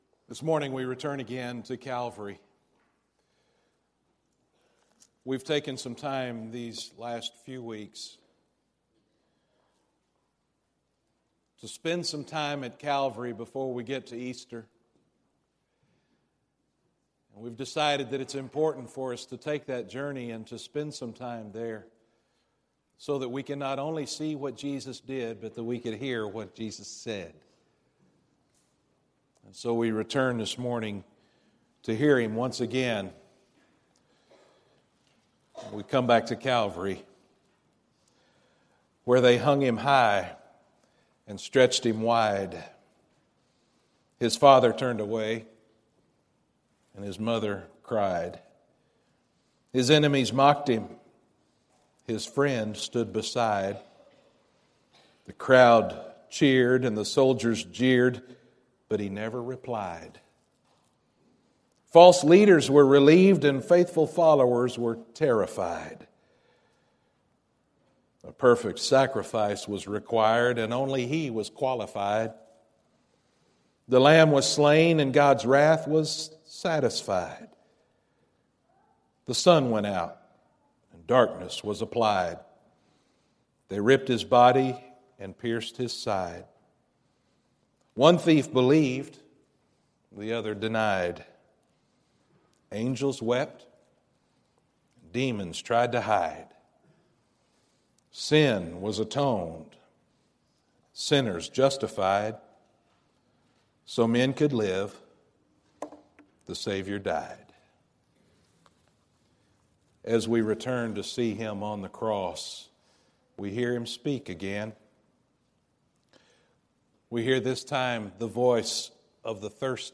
In this message, we hear the last three things that Jesus said from cross. This was actually three mini-sermons that were preached at different times during the